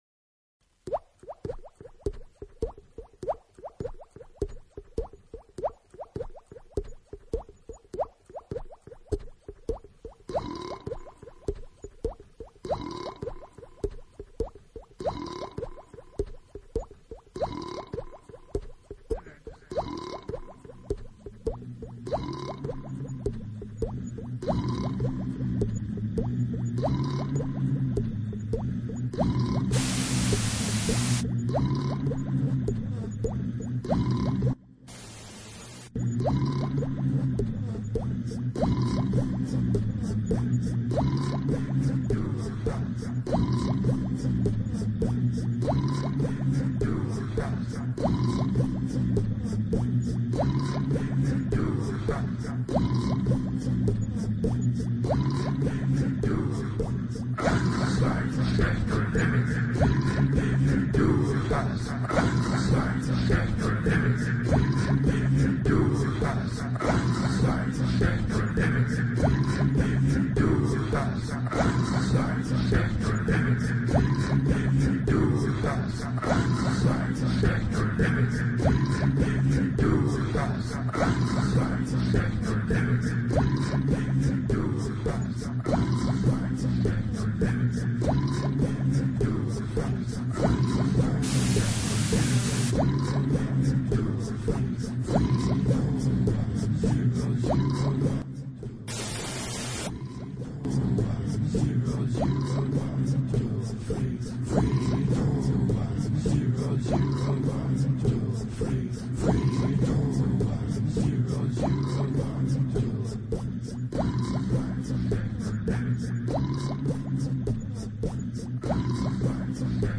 Форум российского битбокс портала » Реорганизация форума - РЕСТАВРАЦИЯ » Выкладываем видео / аудио с битбоксом » Баловство (Трек в Amiloop)
Дрибизжание из-за бета-рекордера.